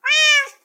cat_meow4.ogg